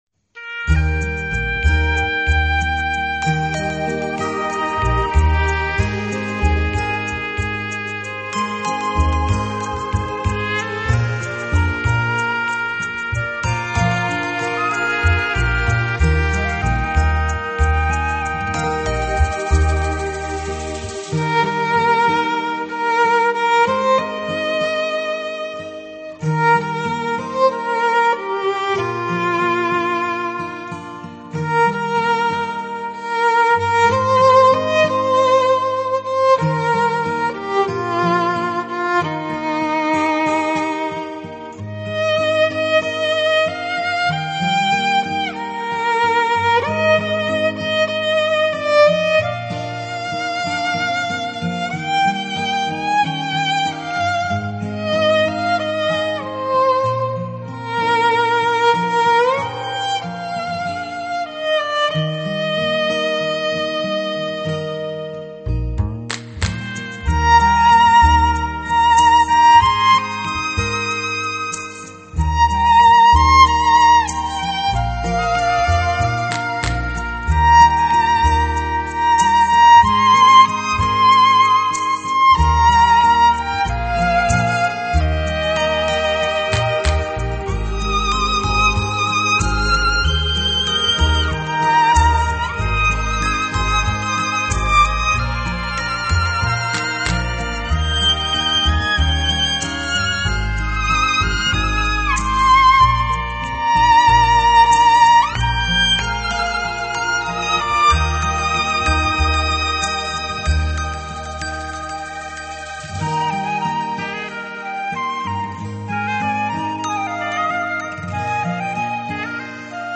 中西乐器演绎经典，DSD直接刻录，高度传真，完美尽现！